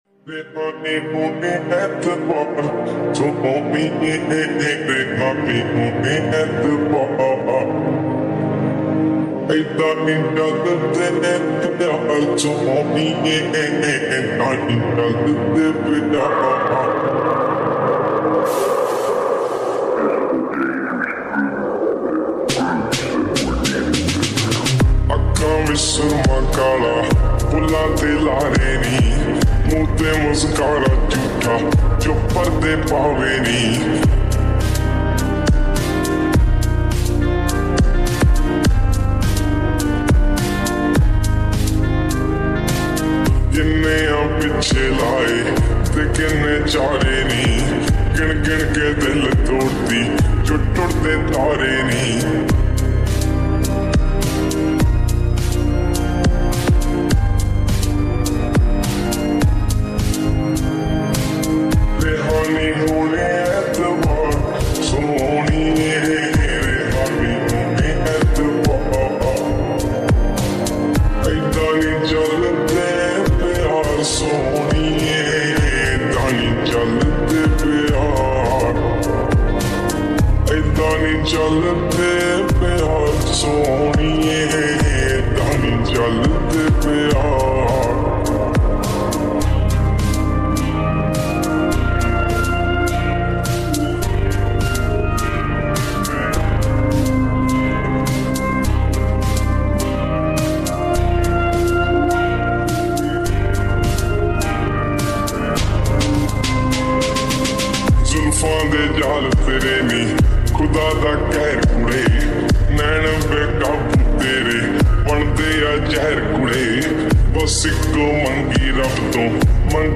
Slowed And Reverb
Most trinding song .Most Heart Touching Song .